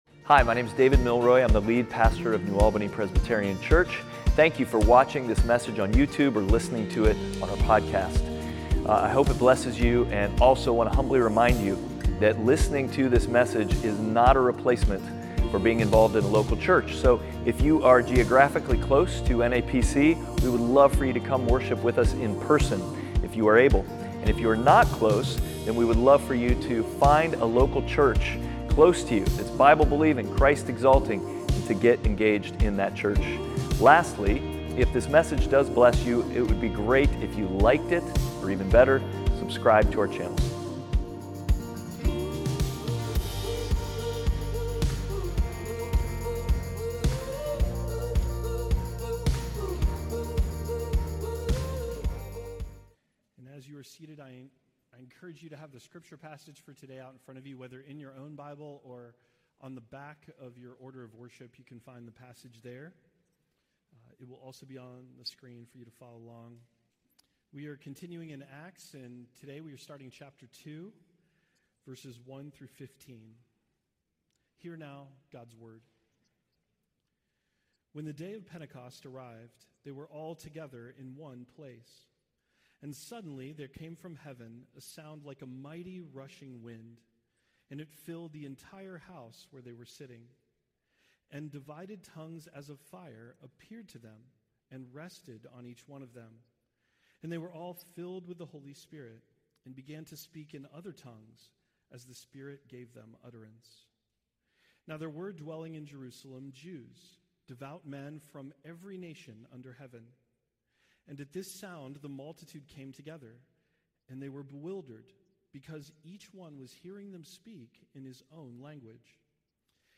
Outward Passage: Acts 2:14-24 Service Type: Sunday Worship « Outward